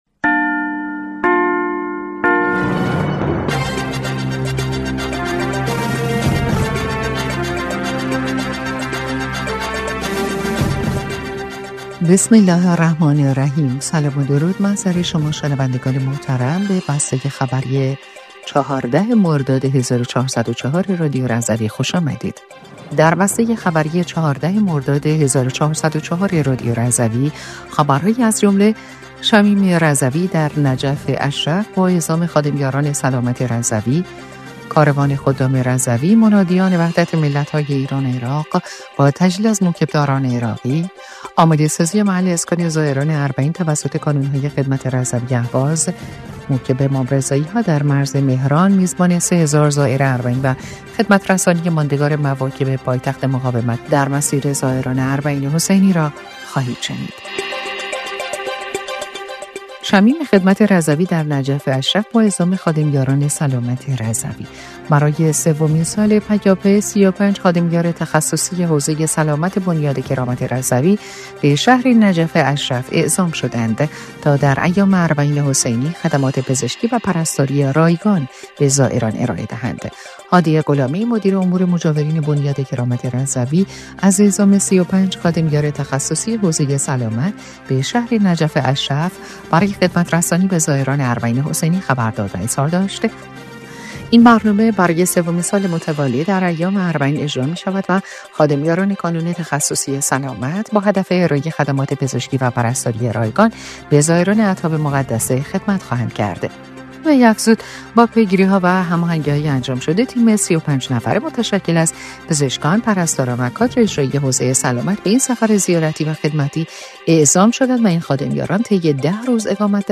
بسته خبری ۱۴ مرداد ۱۴۰۴ رادیو رضوی/